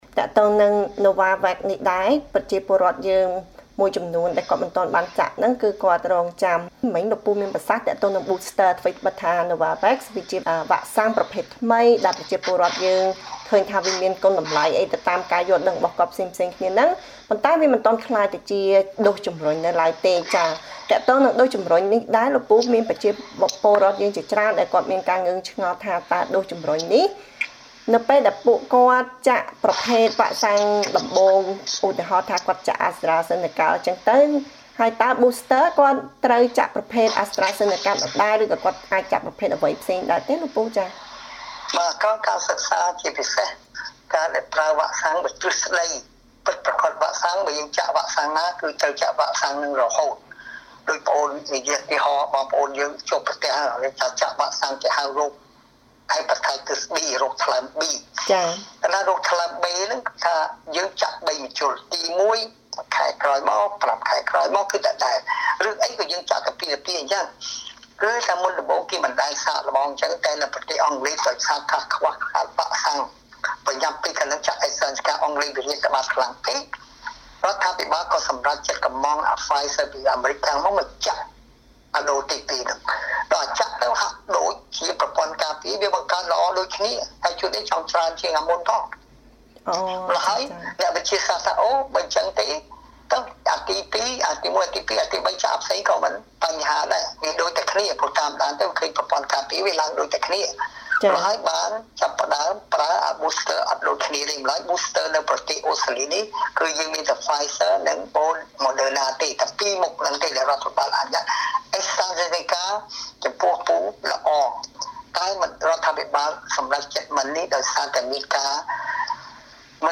បទសម្ភាសន៍ជាមួយឱសថការី